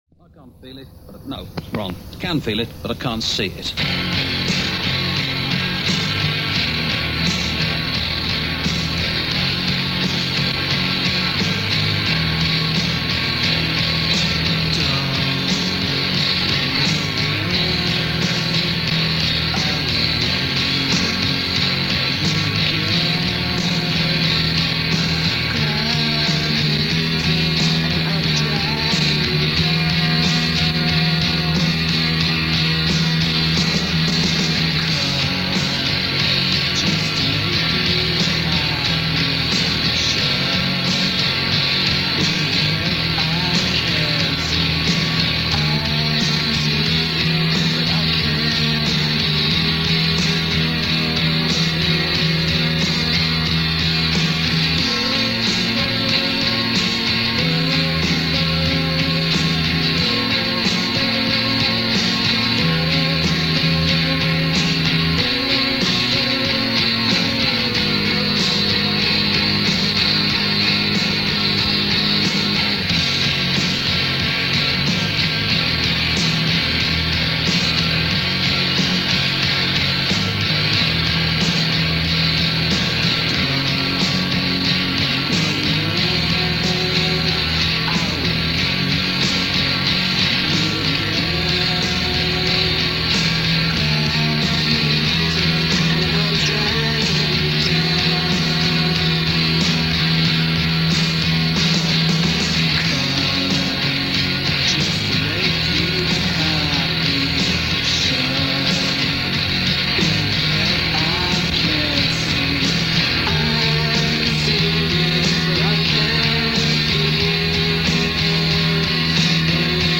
Shoegaze
an unorthodox and unforgettable mixture of noise and melody.